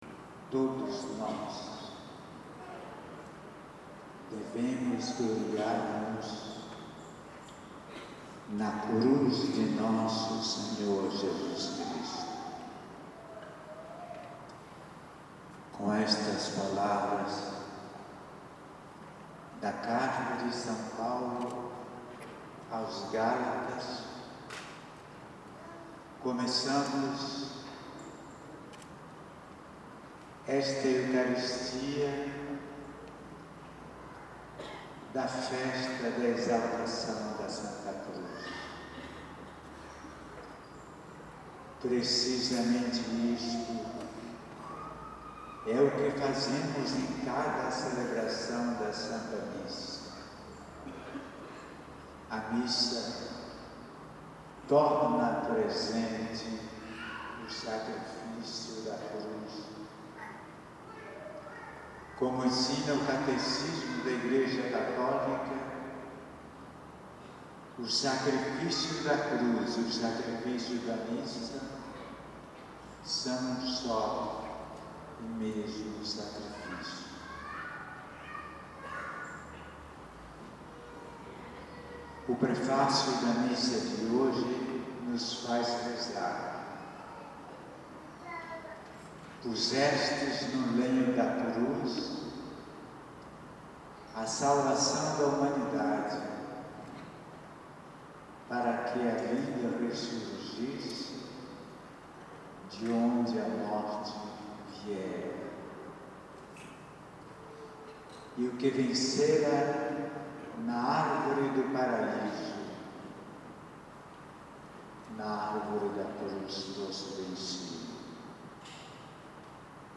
Homilias
Homilia na ordenação presbiteral dos diáconos